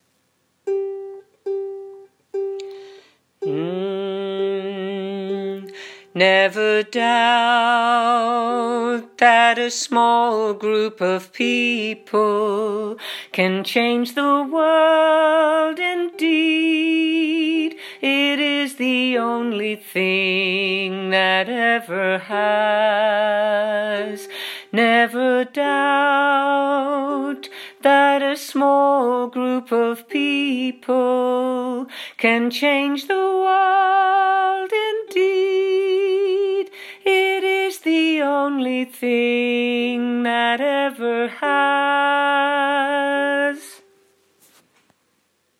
sung by me in a lower key than in the UK Soundcloud version